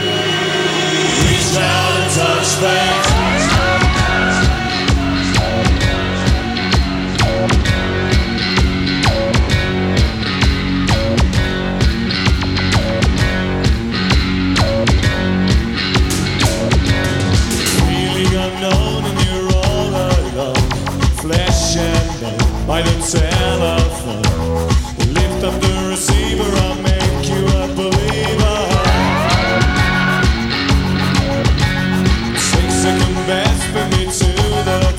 Жанр: Электроника
# Electronic